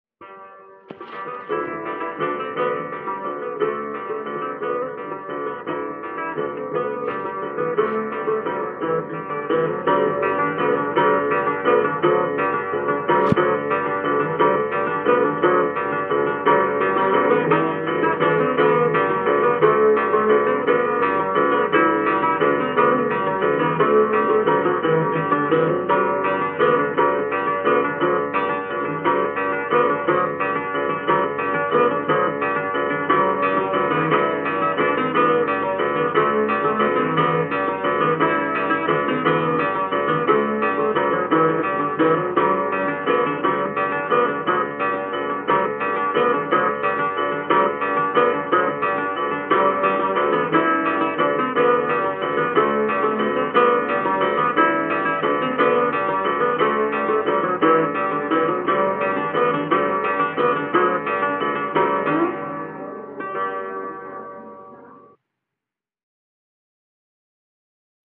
Valsa